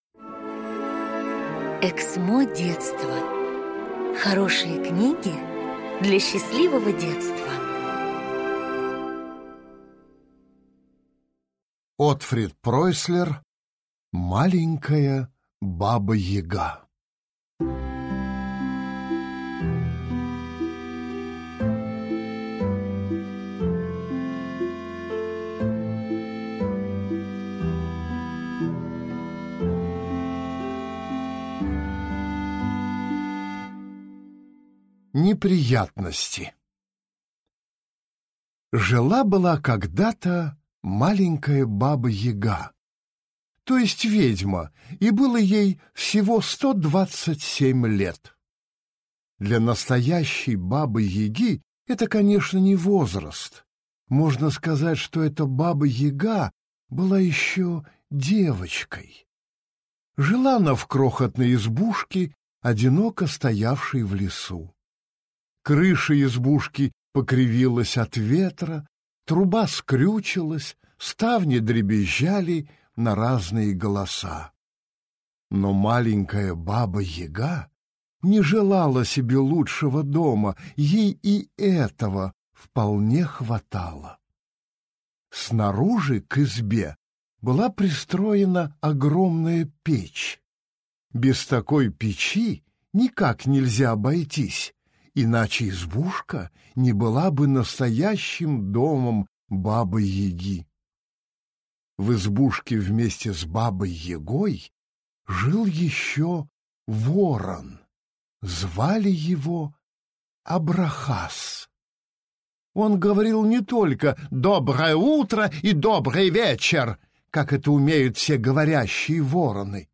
Аудиокнига Маленькая Баба-Яга | Библиотека аудиокниг